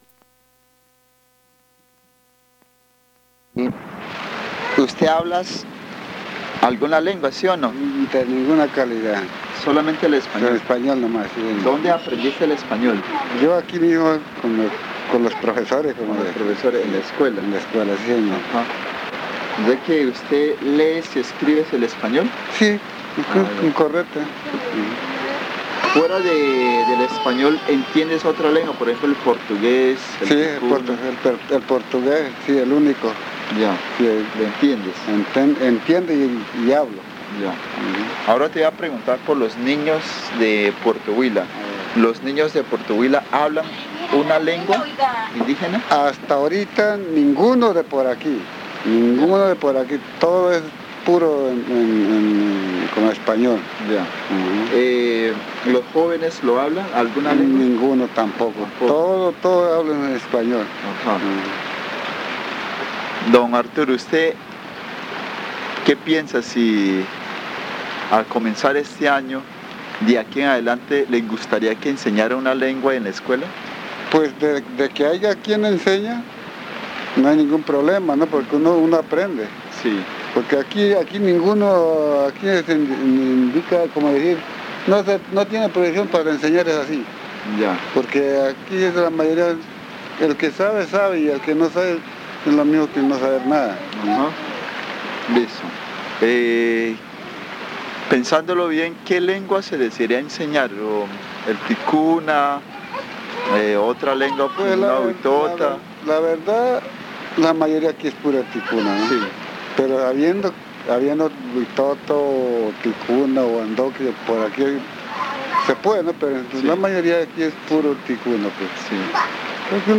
Encuesta léxica y gramatical 9. Puerto Huila y Puerto Nuevo
El audio contiene solo el lado B, ya que el lado A se encuentra vacío. Se entrevistó a una serie de personas no identificables.